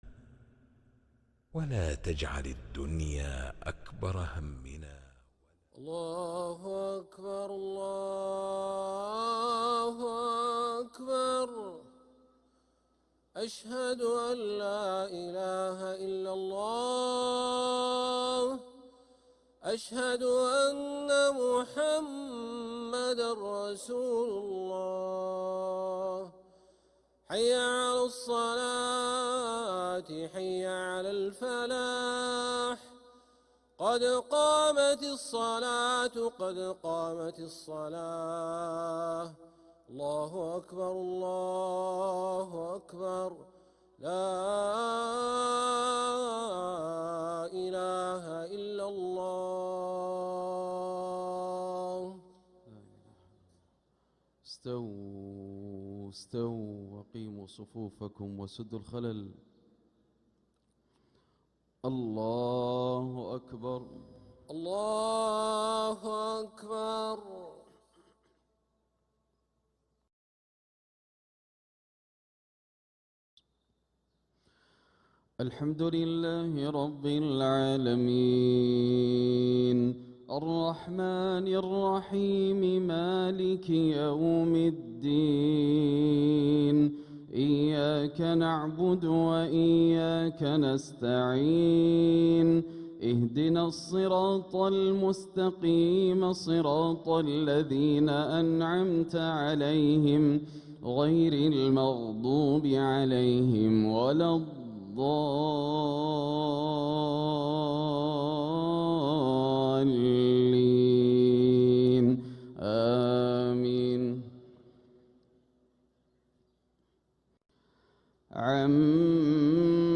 Haramain Salaah Recordings